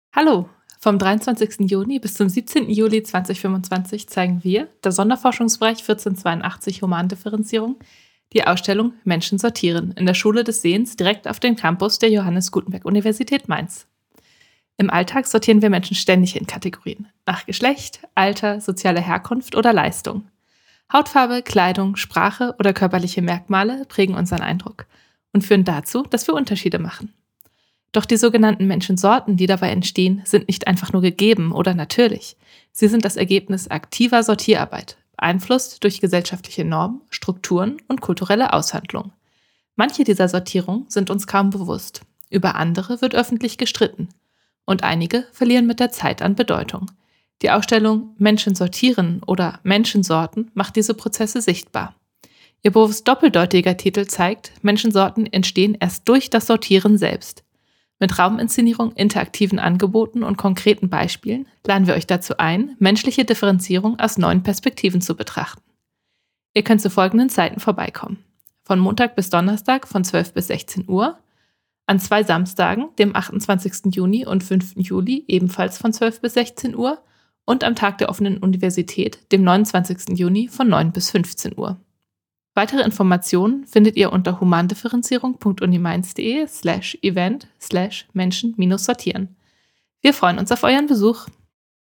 Radio feature by SWR Kultur